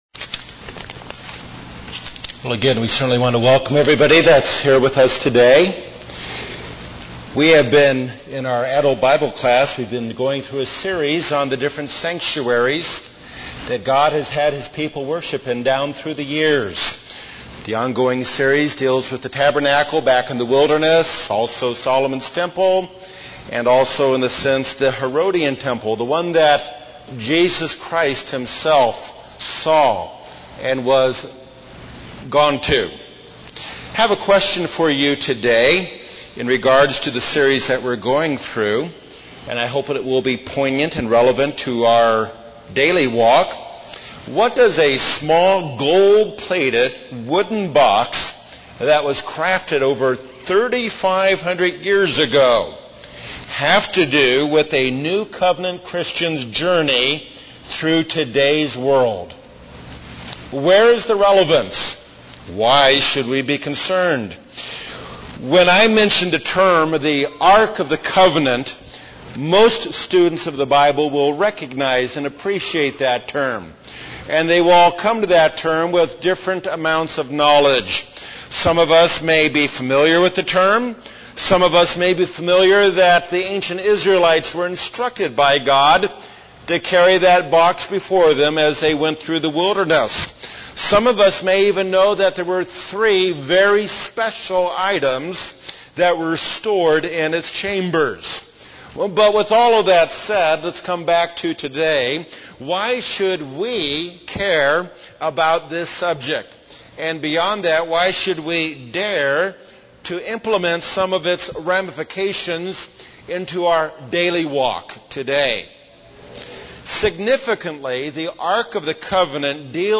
(Lev 11:45, 1 Peter 1:15-16) UCG Sermon Transcript This transcript was generated by AI and may contain errors.